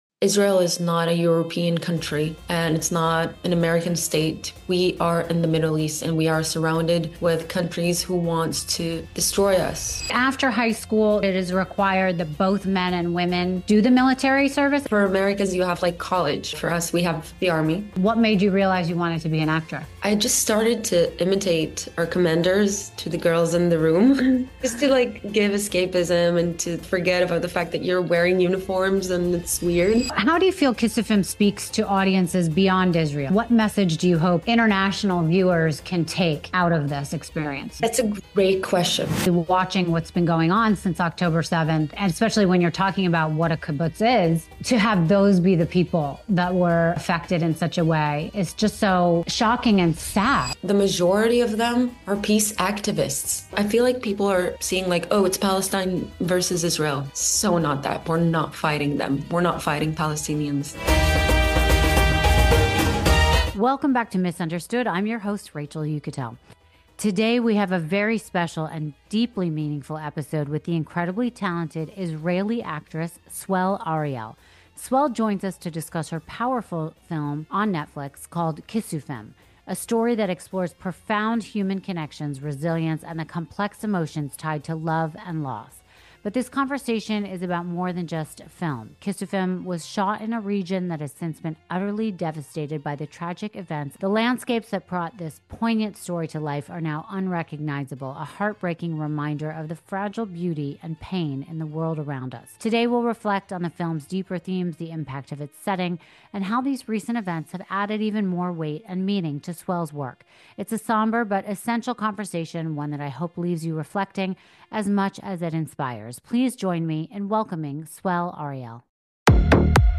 Today on Miss Understood, I’m joined by the brilliant Israeli actress Swell Ariel to discuss her powerful Netflix film, Kissufim. The story delves into love, loss, and resilience, but its impact is even deeper now.
Join us for this deeply moving conversation about art, humanity, and the enduring connections that bind us.”